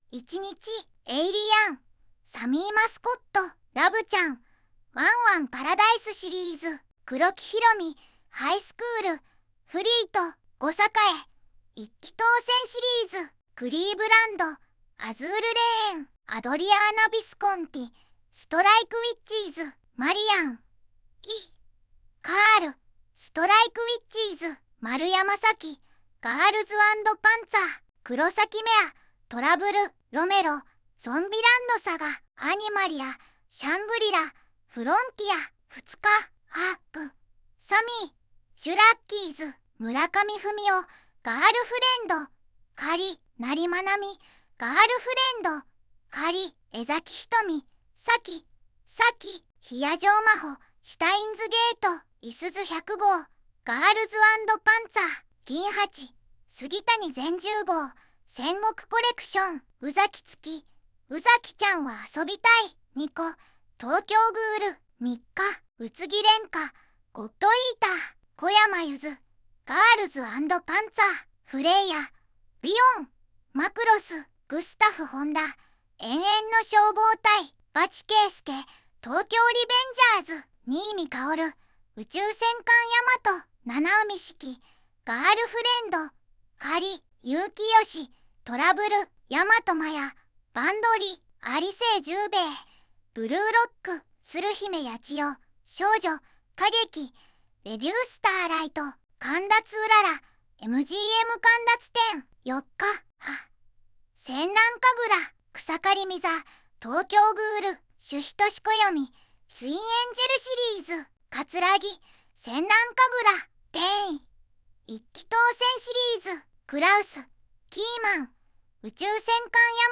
VOICEVOX: ずんだもんを利用しています